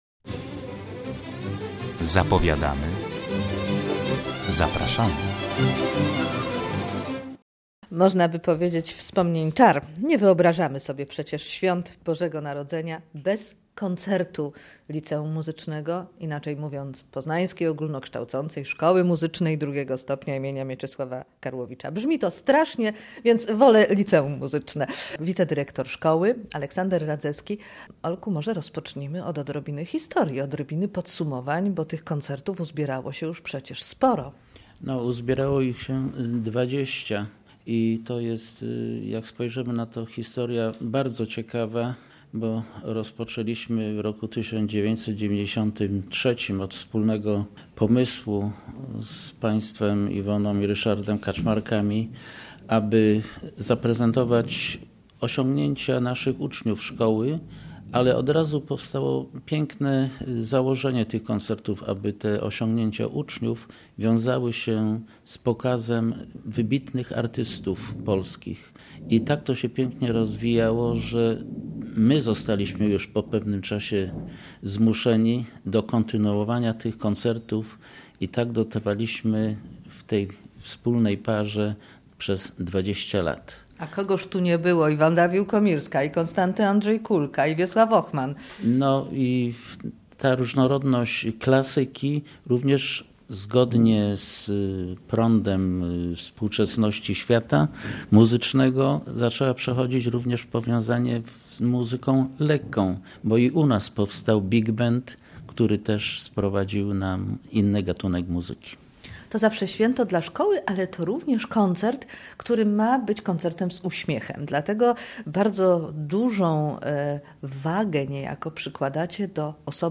Koncert Gwiazdkowy w Auli UAM